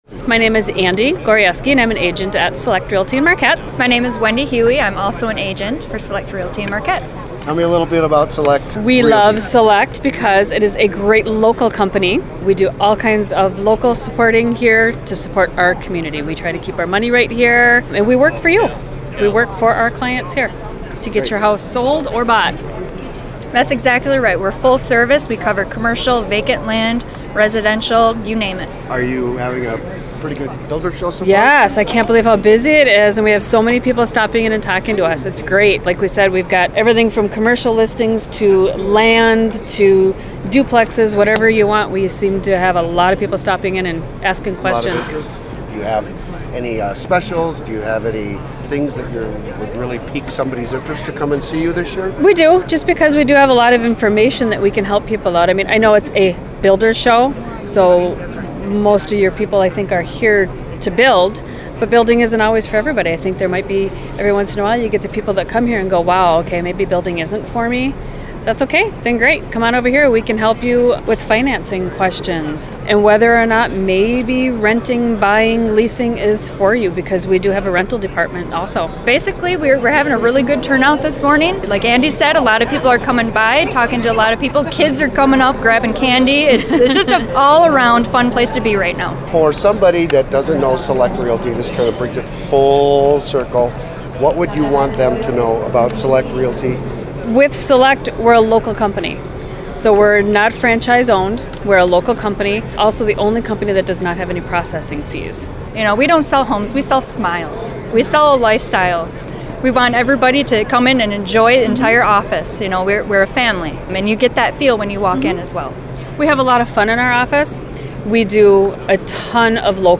Select_Realty_UP_Builders_Show_2014.MP3